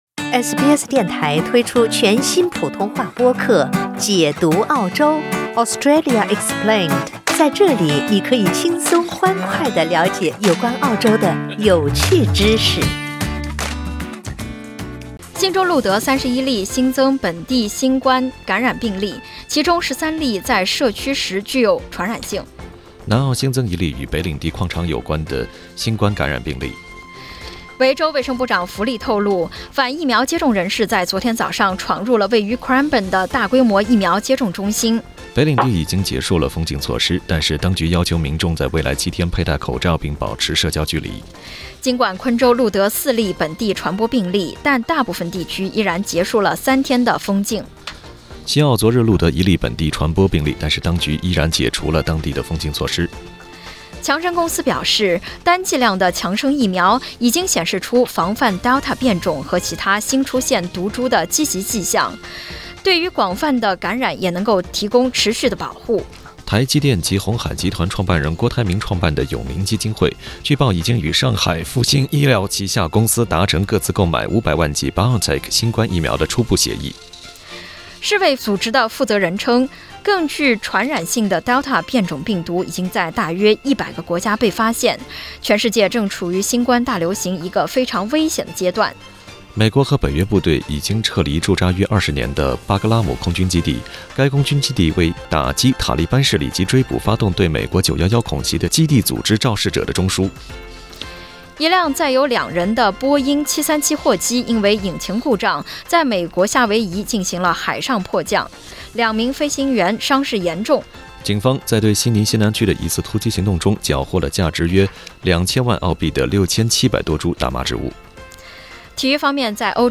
SBS早新聞（7月3日）
SBS Mandarin morning news Source: Getty Images